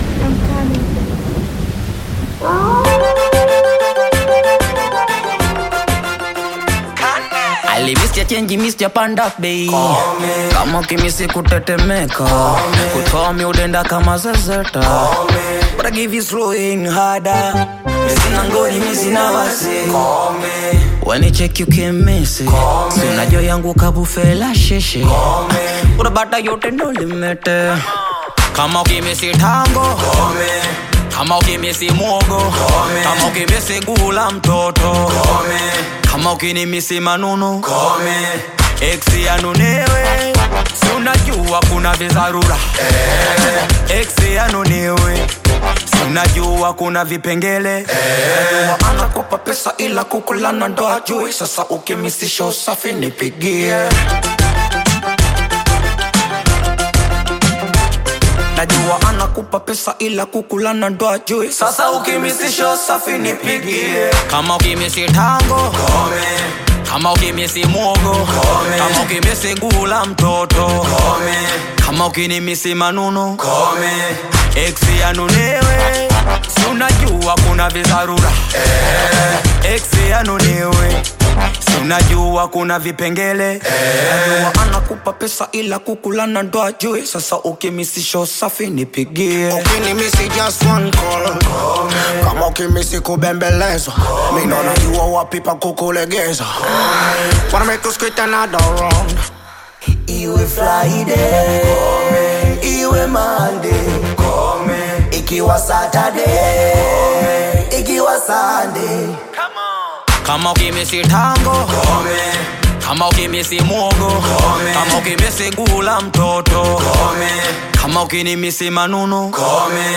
Tanzanian music duo